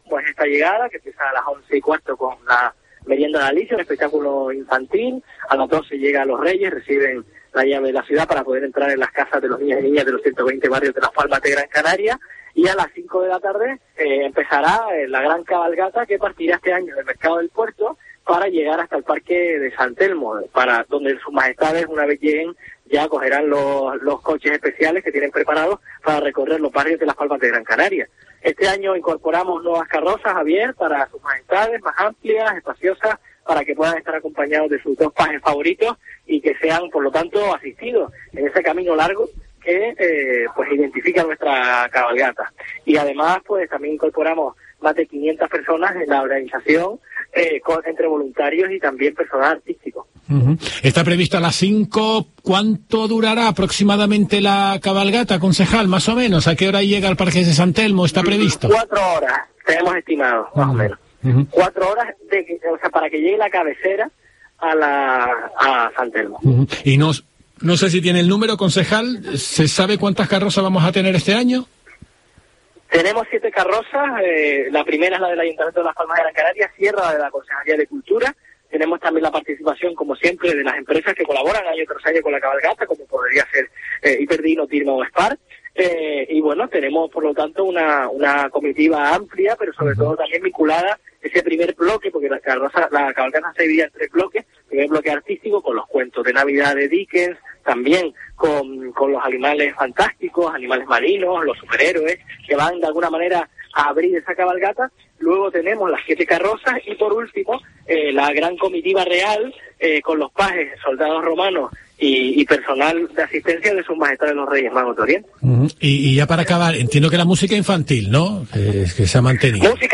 Adrián Santana, concejal de Cultura del ayuntamiento de Las Palmas de Gran Canaria